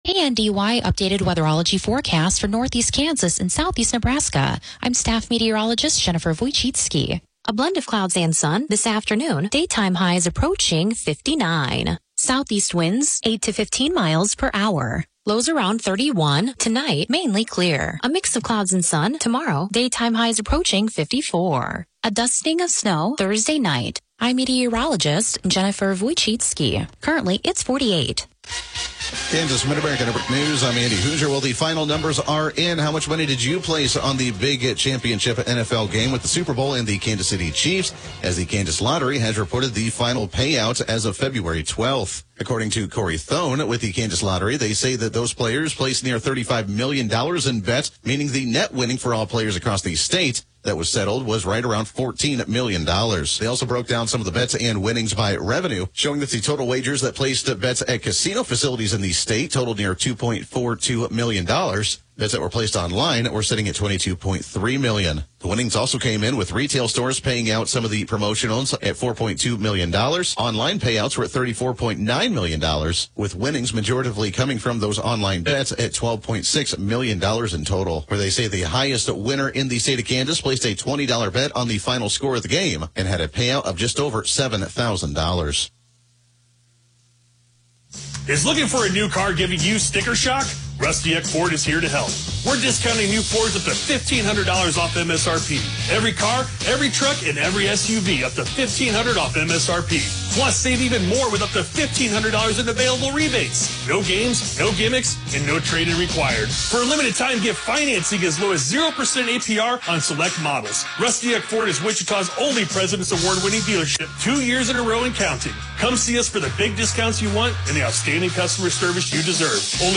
Broadcasts are archived daily as originally broadcast on Classic Country AM 1570/FM 94.1 KNDY.